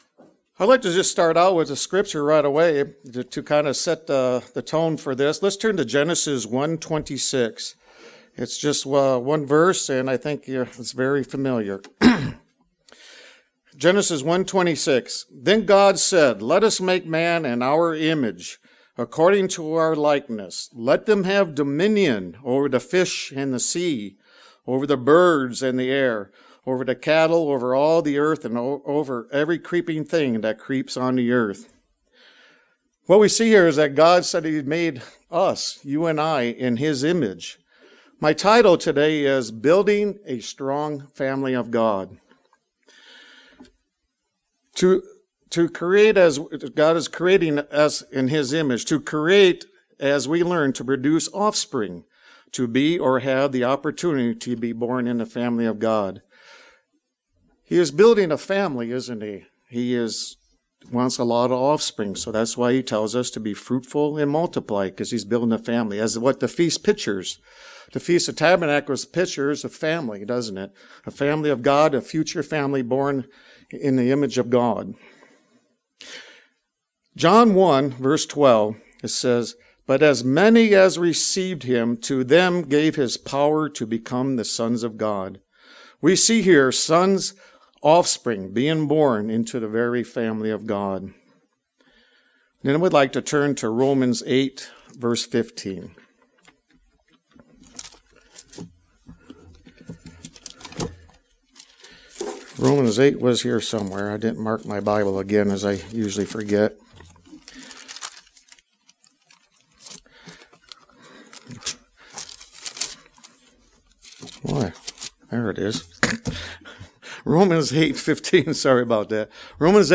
Sermons
Given in Northwest Arkansas